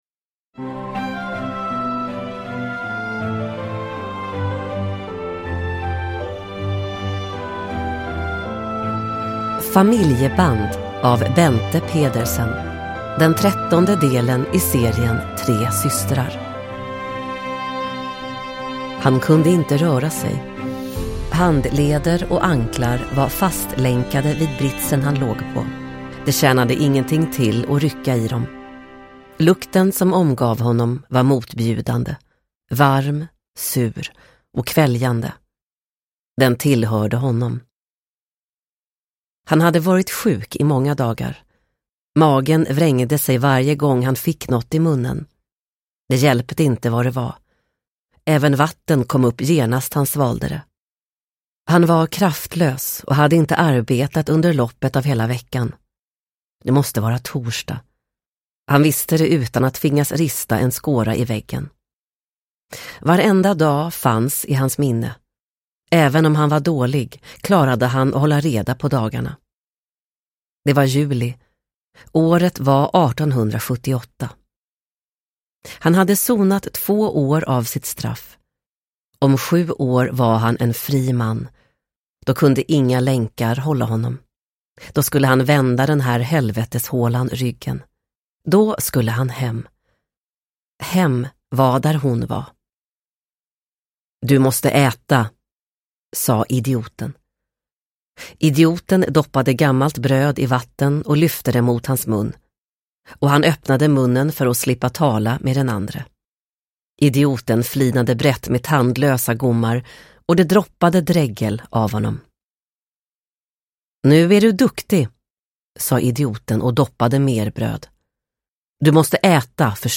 Familjeband – Ljudbok – Laddas ner